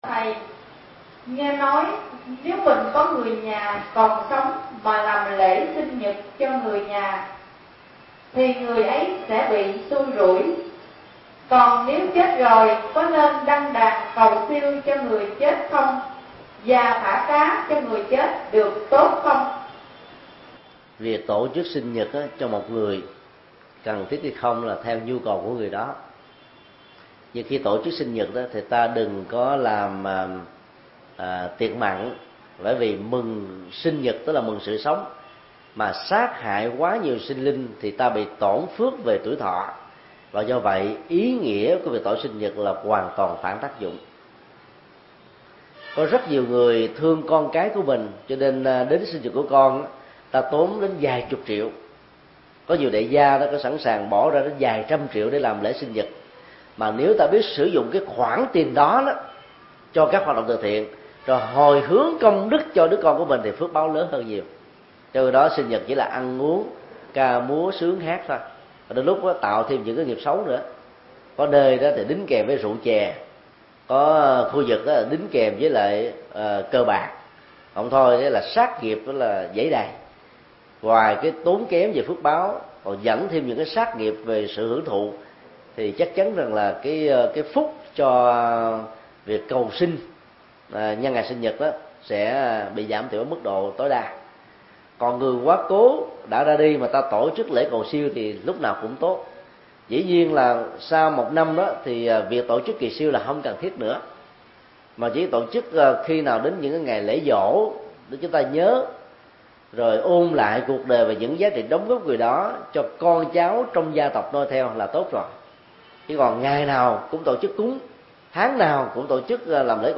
Vấn đáp: Tổ chức sinh nhật và lễ cầu siêu